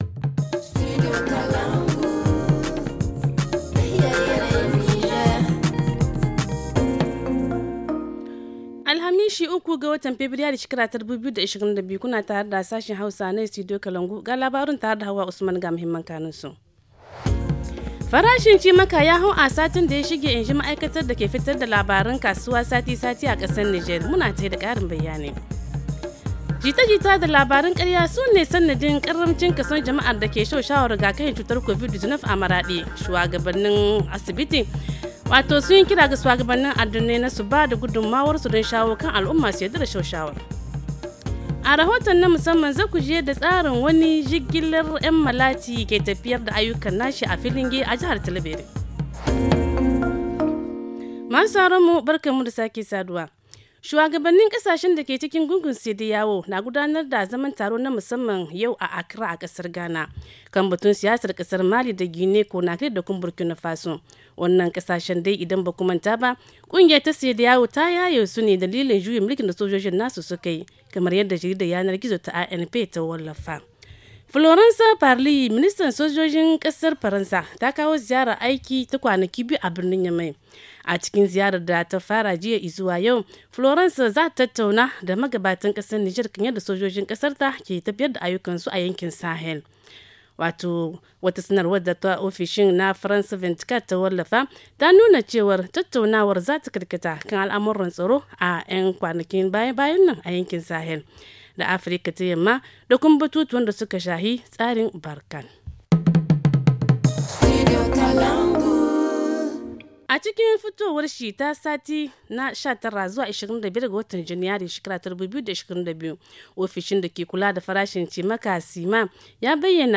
Le journal du 3 février 2022 - Studio Kalangou - Au rythme du Niger